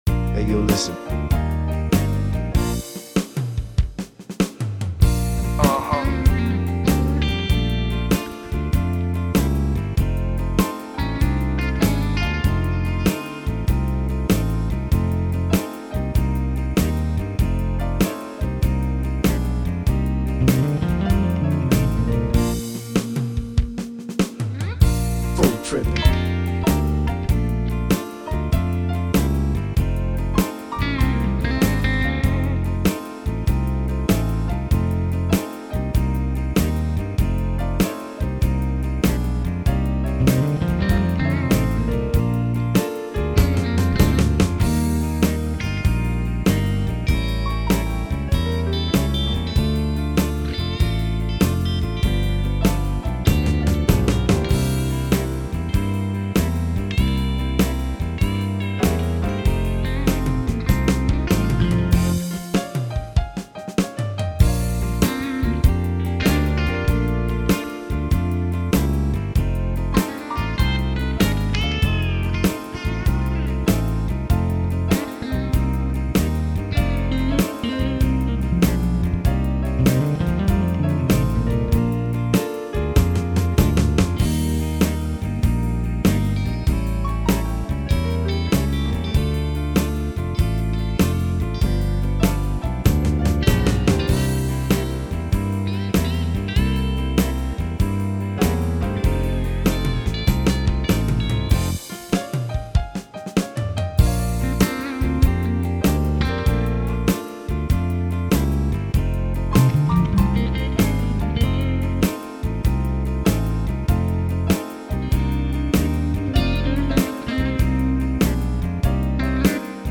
Song style: pop